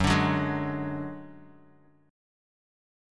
Gbsus2#5 chord